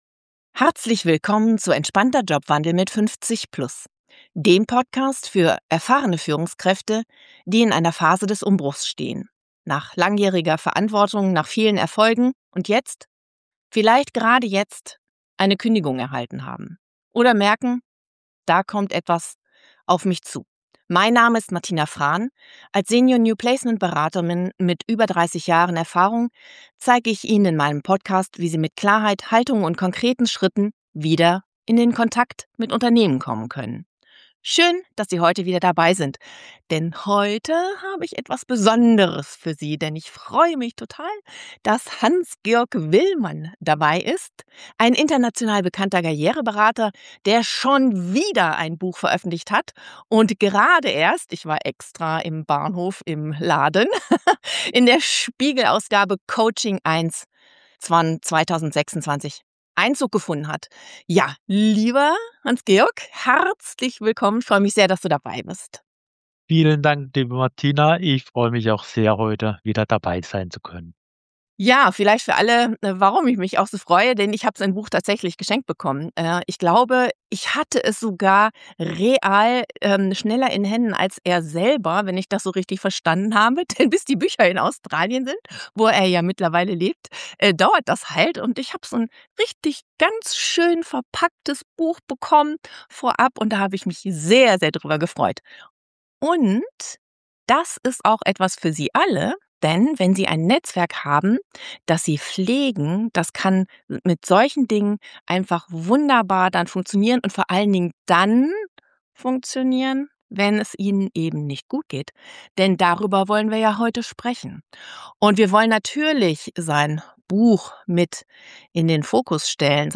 Gerade während oder nach einer Kündigung. Ein Gespräch für alle, die in diese belastenden Situation nach Orientierung und Mut suchen.